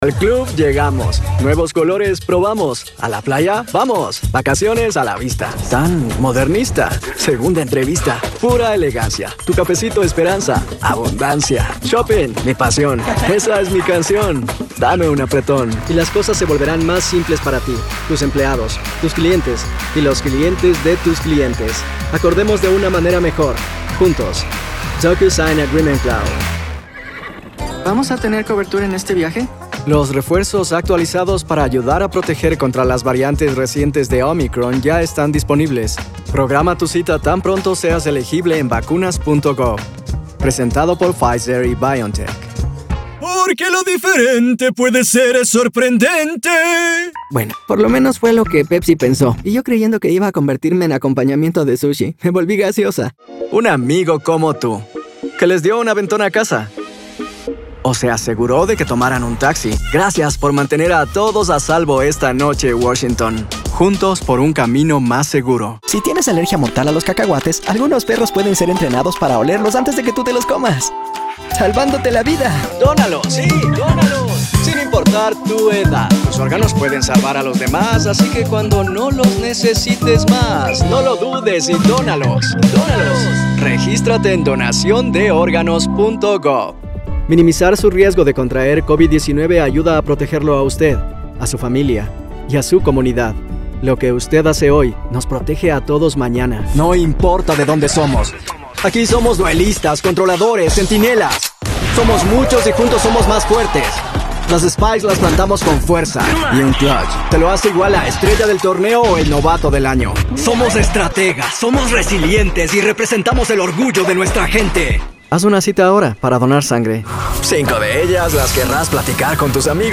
Demos
Commercial Reel
Spanish Neutral, Mexican, Castilian, English Latino